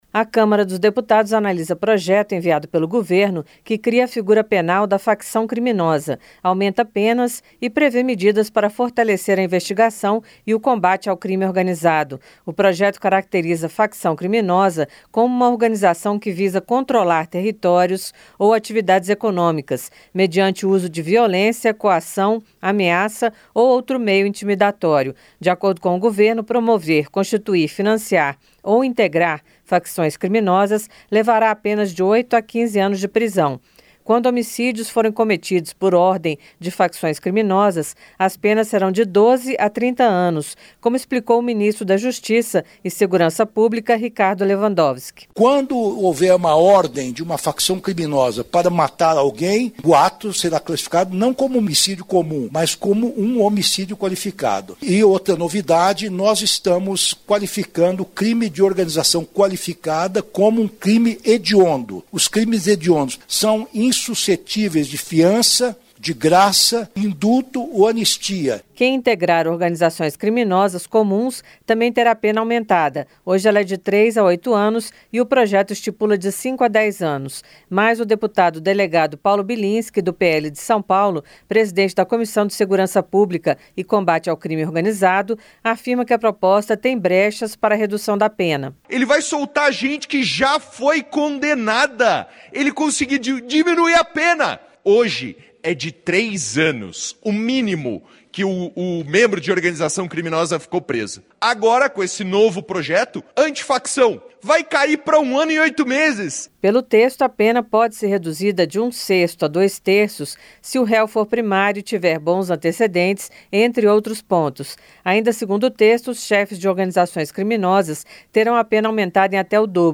O GOVERNO ENCAMINHOU PARA A AVALIAÇÃO DA CÂMARA DOS DEPUTADOS, PROJETO QUE AUMENTA AS PENAS PARA INTEGRANTES DE ORGANIZAÇÕES CRIMINOSAS. A REPORTAGEM